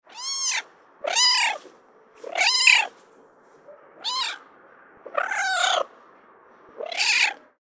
جلوه های صوتی
دانلود صدای گربه ملوس و کوچولو از ساعد نیوز با لینک مستقیم و کیفیت بالا